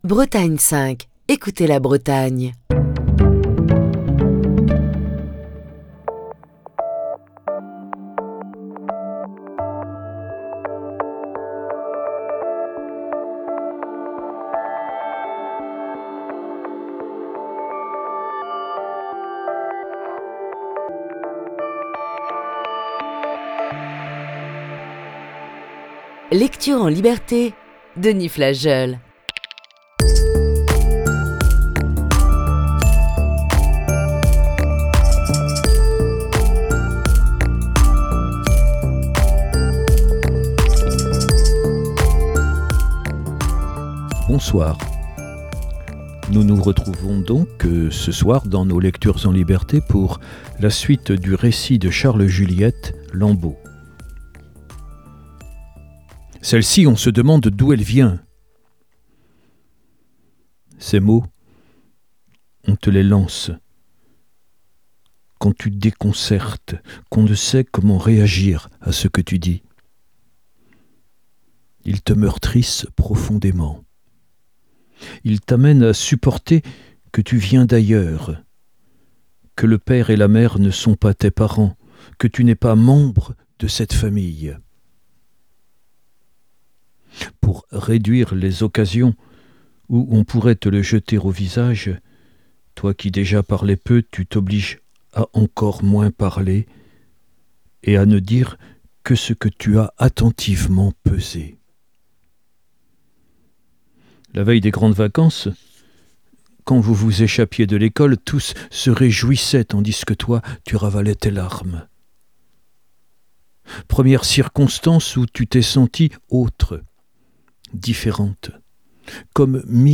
Émission du 22 février 2022.